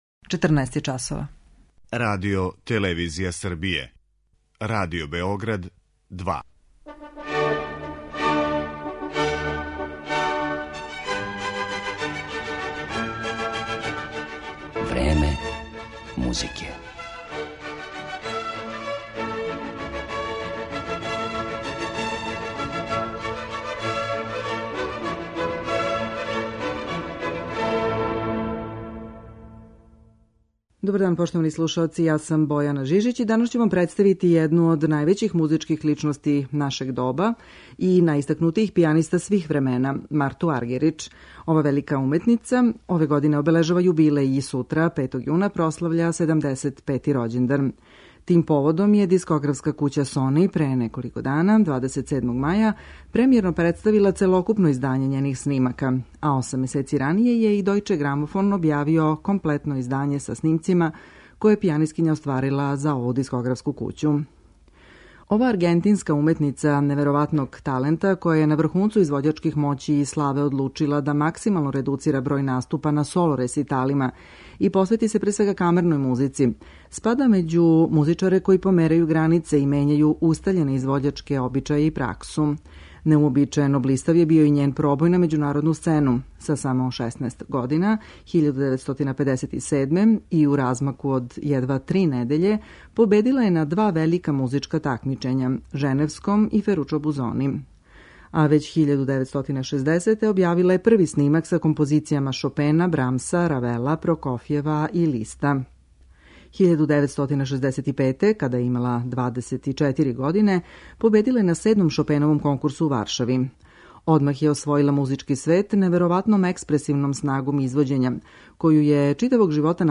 Харизматичној аргентинској пијанисткињи и једној од највећих и најоригиналнијих музичких личности нашег доба, Марти Аргерич, посвећена је данашња емисија.
У Времену музике представићемо је у разноврсним и улогама и репертоару и слушаћете је како, као солиста или у сарадњи са другим музичарима, изводи дела Шопена, Бетовена, Франка, Прокофјева и Листа.